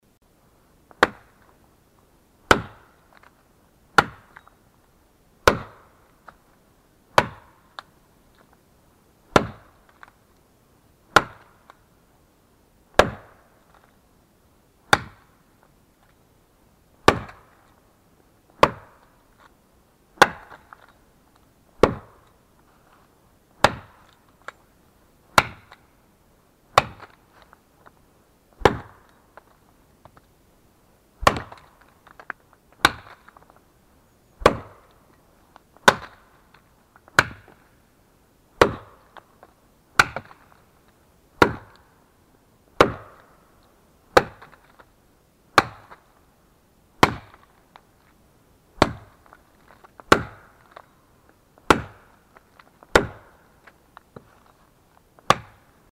Звуки топора
На этой странице собраны разнообразные звуки, связанные с работой топора: от мощных ударов по дереву до звонкого отскока лезвия.
Рубят сухое дерево в лесной чаще